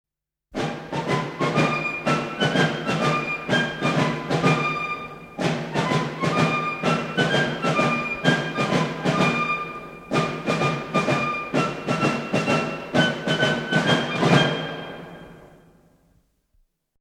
Méthode de Tambour d’ordonnance
Le ban : quarante-cinq coups doubles, divisés en trois fois, commençant toujours d'en bas, et à la fin un rat de 4 :
Un rat de 4 (= ta = ta ta = ta =).